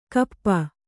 ♪ kappa